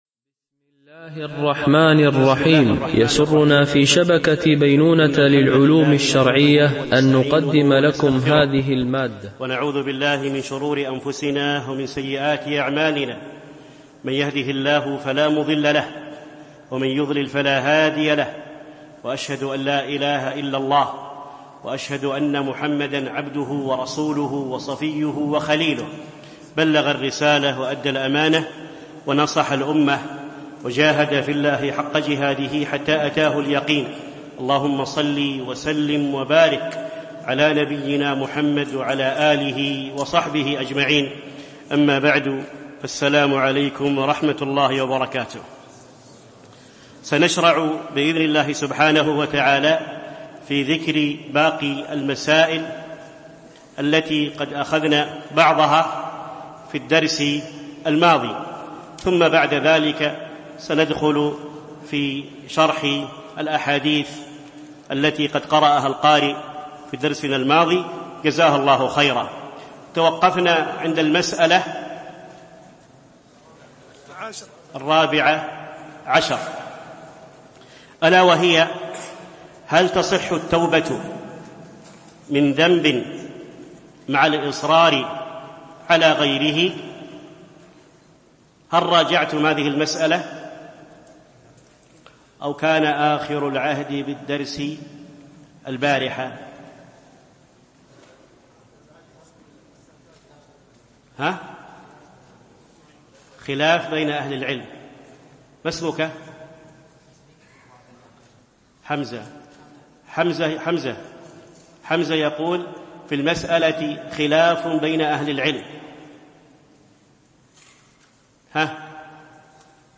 دورة علمية شرعية لمجموعة من المشايخ الفضلاء بمسجد أم المؤمنين عائشة - دبي (القوز 4)